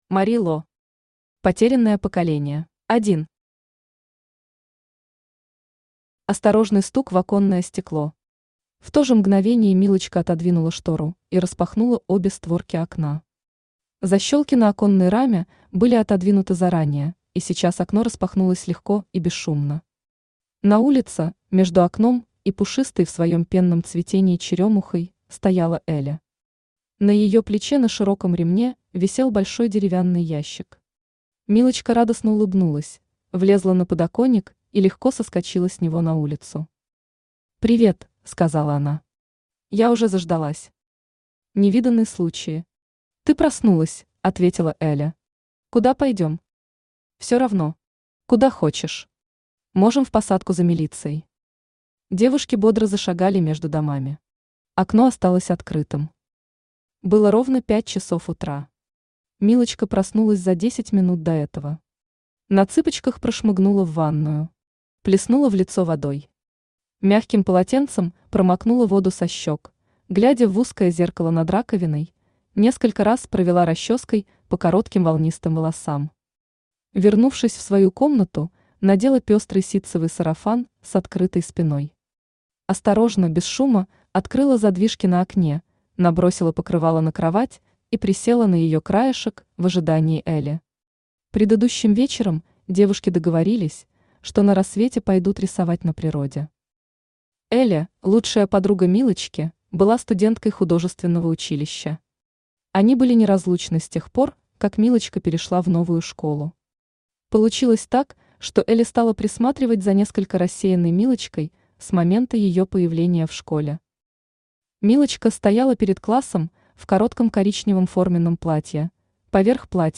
Аудиокнига Потерянное поколение | Библиотека аудиокниг
Aудиокнига Потерянное поколение Автор Мари Ло Читает аудиокнигу Авточтец ЛитРес.